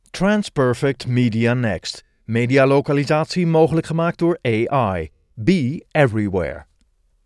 Promos
I have a friendly, knowledgeable, dynamic and/or emotional voice, but I can strike a lot of other tones as well.
- Soundproof home studio
Baritone